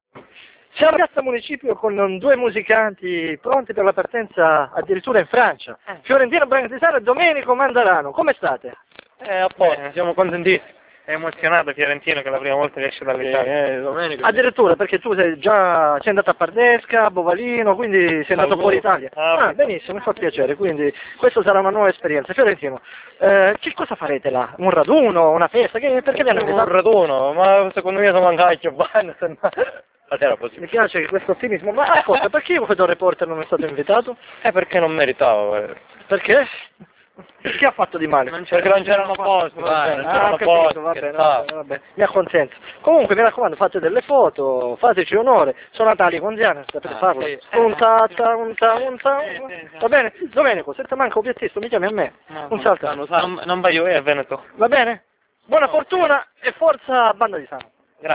Audio banda musicale di Samo